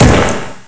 snd_203_Water Kick.wav